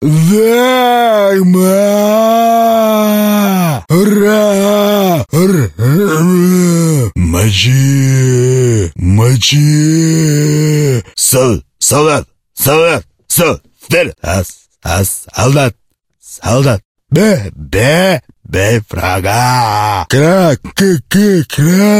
Звуки зомби
Здесь собраны жуткие стоны, агрессивное рычание, скрежет зубов и другие пугающие эффекты, которые помогут создать напряженную обстановку в вашем проекте.
Звук зомби из Сталкера жуткий и пронзительный